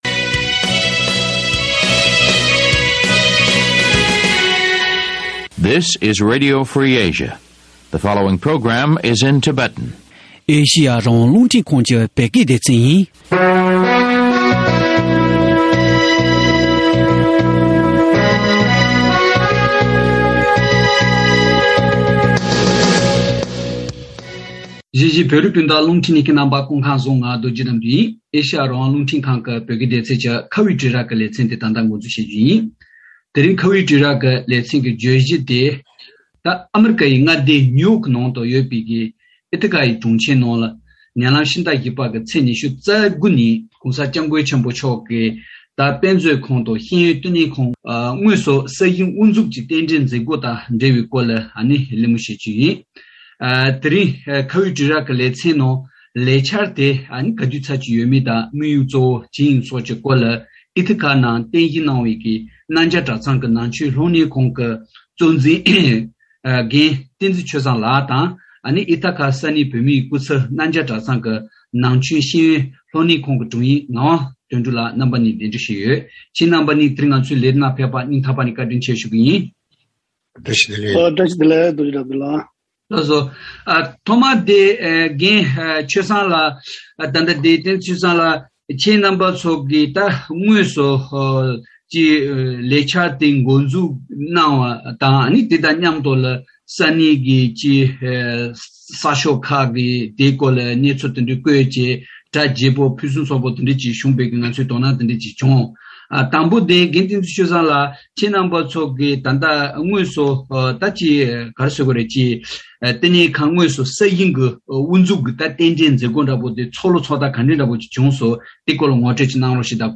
ཨི་ཐ་ཀ་གྲོང་ཁྱེར་ནང་བཞེང་རྒྱུའི་རྒྱལ་མཆོག་སྐུ་ཕྲེང་༡༤པ་ཆེན་པོ་མཆོག་གི་དཔེ་མཛོད་ཁང་དང་ཤེས་ཡོན་ལྟེ་གནས་དེའི་གནད་འགག་དང་དམིངས་ཡུལ་ཐད་གླེང་མོལ་ཞུས་པ།